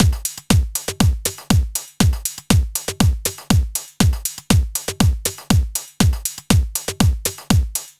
Session 14 - Mixed Beat 02.wav